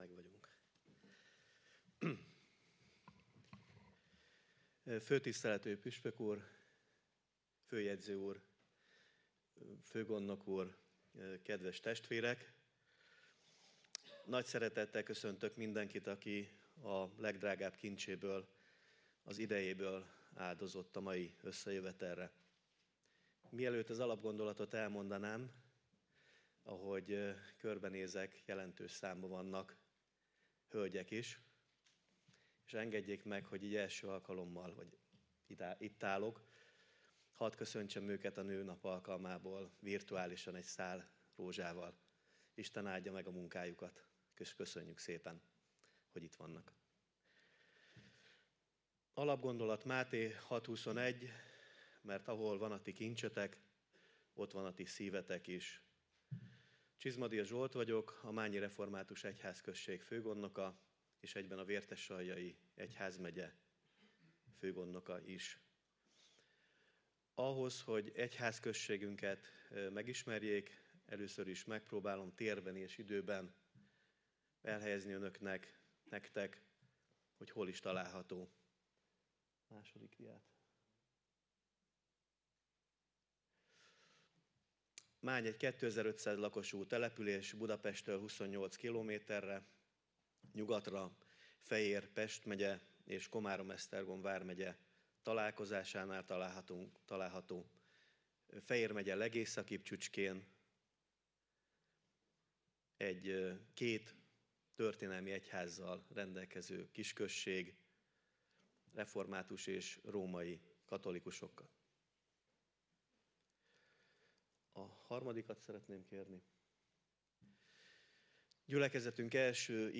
A 2025. március 8-án tartott egyházkerületi főgondnoki konferencián elhangzott előadások, és bemutatott prezentációk iránt a jelen voltak közül is sokan érdeklődtek, jelezve, hogy szívesen...